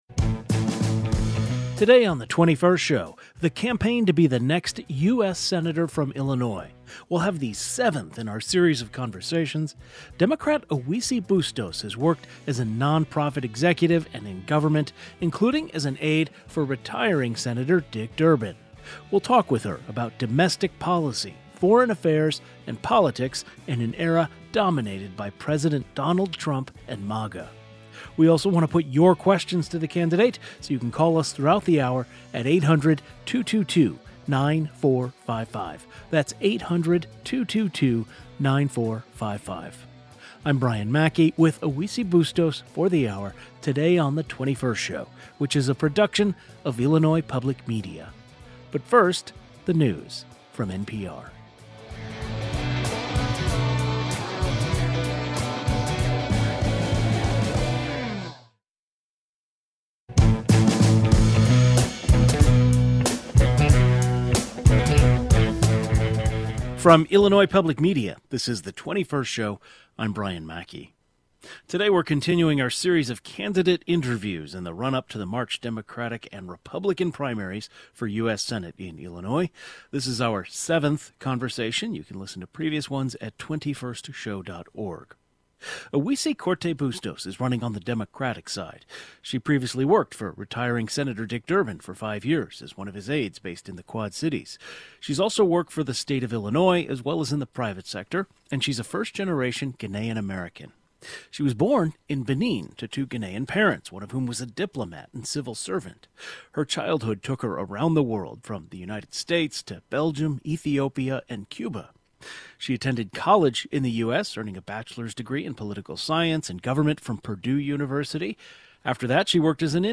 We’re continuing our series of candidate interviews in the run-up to the March Democratic and Republican primaries for U.S. Senate in Illinois.